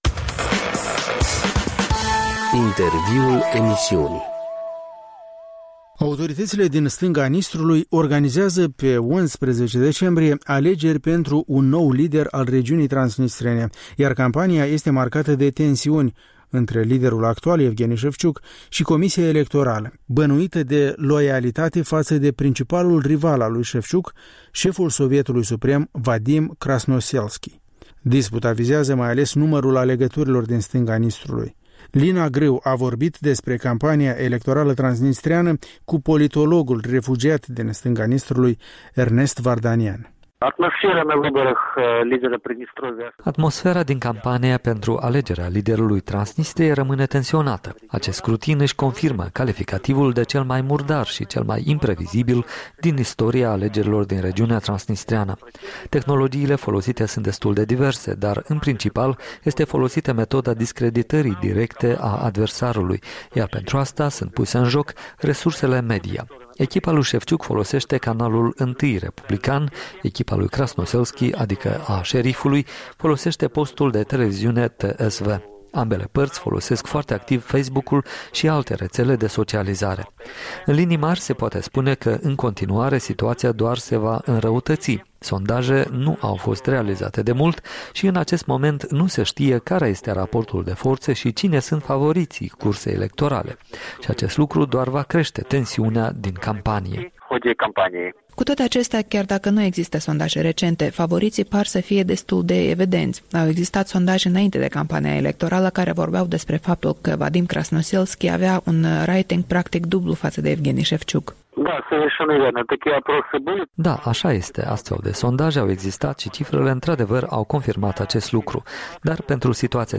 De vorbă cu politologul refugiat din regiunea transnistreană despre apropiatele alegerile din stânga Nistrului.